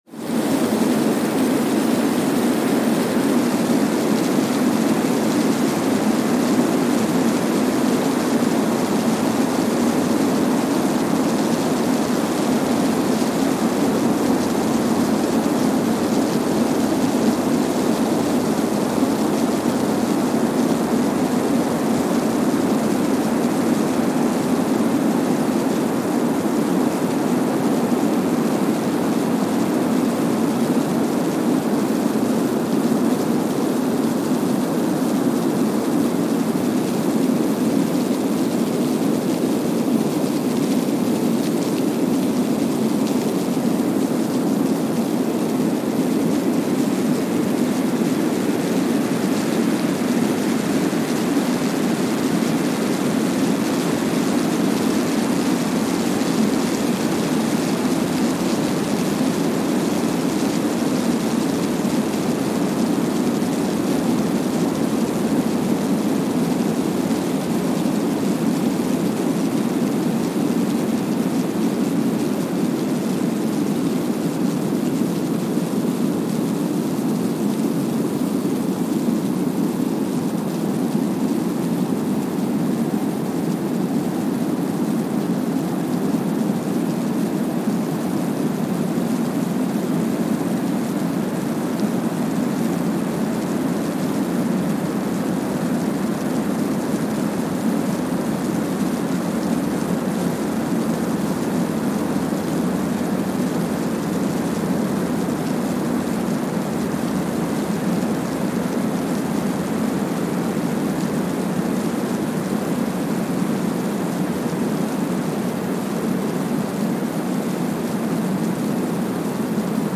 Kwajalein Atoll, Marshall Islands (seismic) archived on November 4, 2020
No events.
Sensor : Streckeisen STS-5A Seismometer
Speedup : ×1,000 (transposed up about 10 octaves)
Loop duration (audio) : 05:45 (stereo)
SoX post-processing : highpass -2 90 highpass -2 90